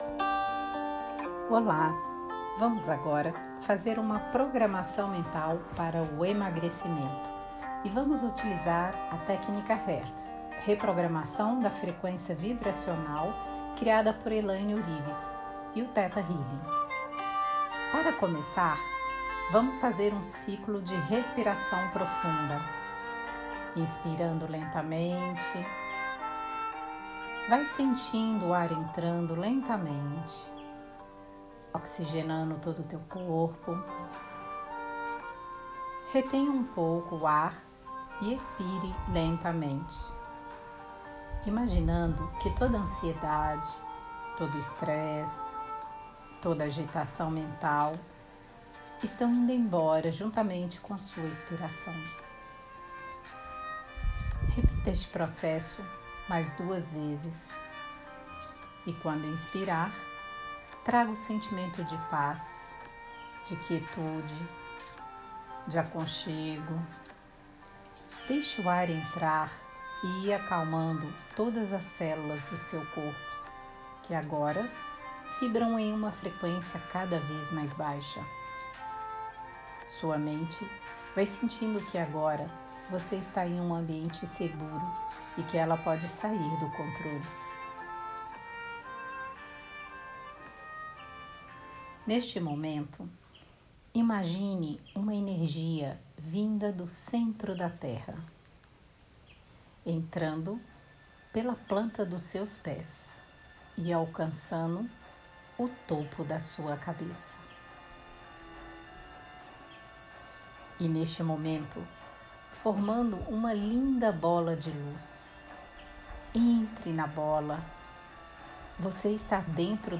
Meditação para emagrecimento: confira abaixo